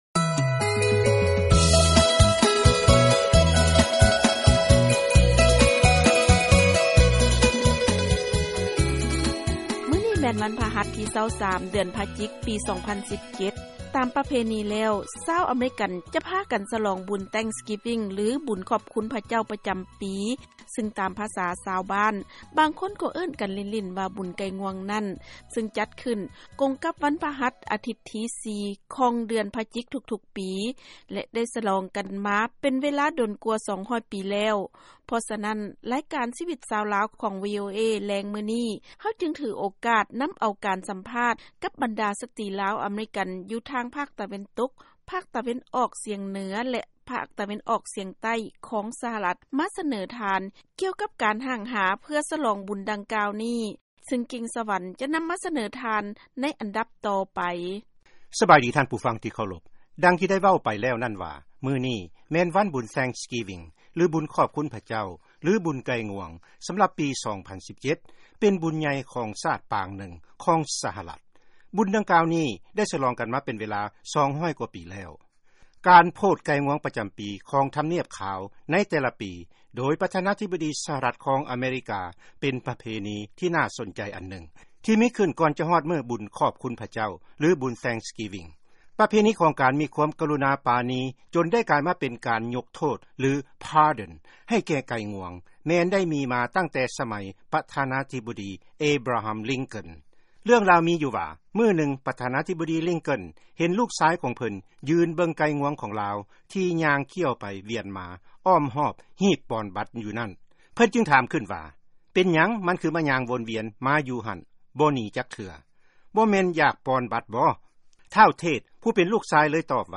ເຊີນຟັງການສຳພາດ ສະຫລອງບຸນວັນຂອບຄຸນພະເຈົ້າ